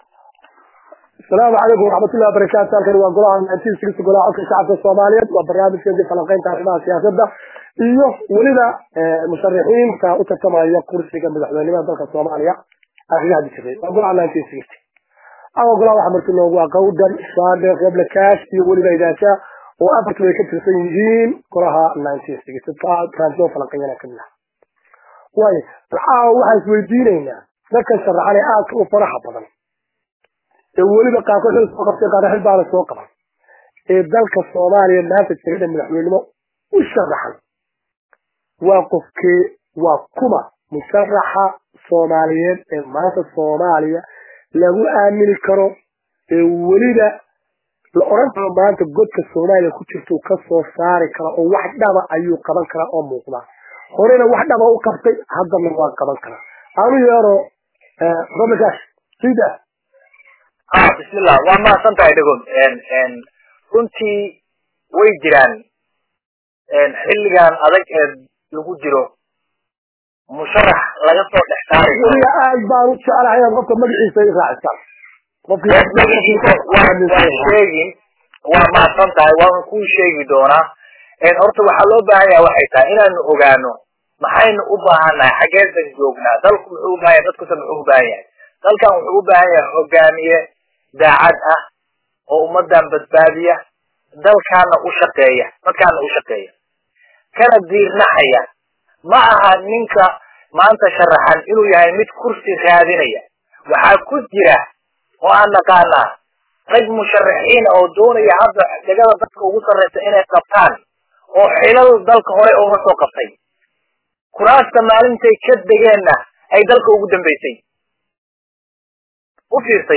3 jan 2017 (Puntlandes) Barnaamijkan oo aad u xiisa badan waxaa kaga dooday golaha1960 aqoonyahana somaliyeed oo madaxbanaan ,si dhab ahna isu dultaagey shaqsiyaadka ka midka ah musharaxiinta madaxweyne ee sanadakan u sharaxan jagada madaxweyne ee dalka somaliya.